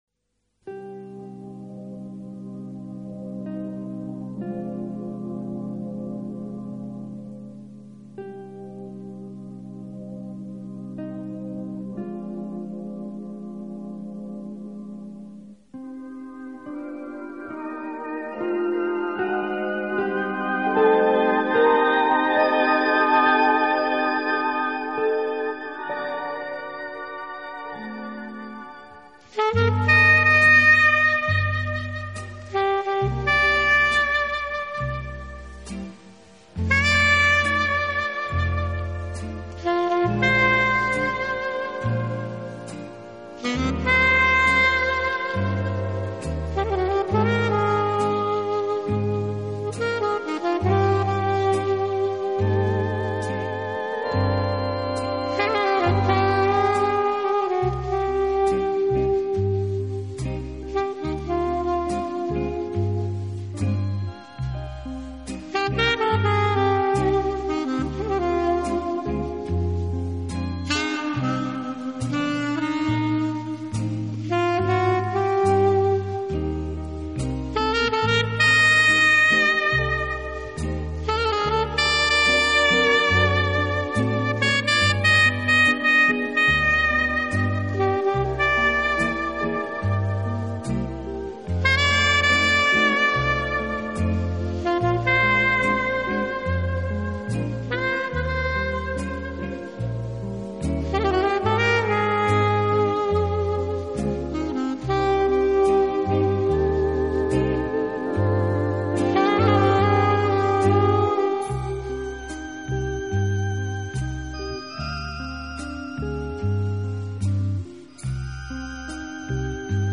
音乐风格：Instrumental/Jazz
是意大利中低音萨克斯演奏家
用萨克管演奏情调爵士乐，上世纪六十年代开始很走红，到上个世纪七十年代达到顶峰，